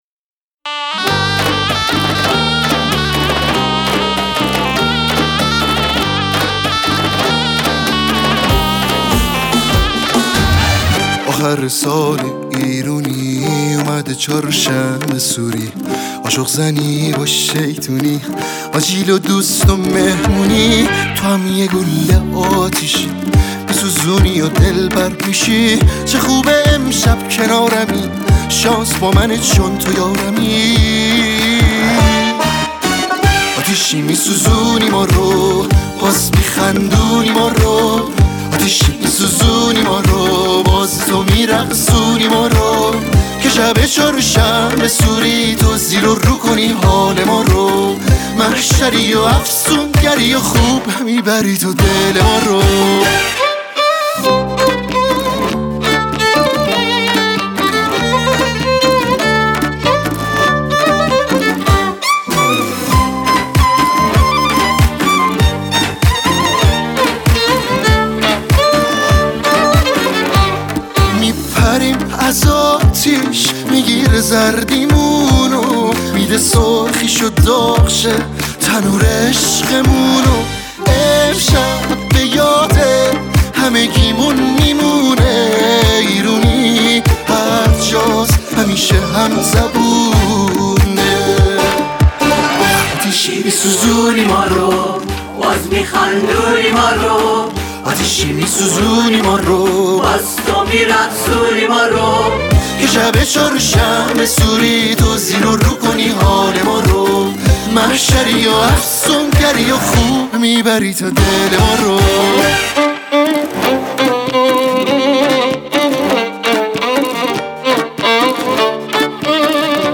بک وکال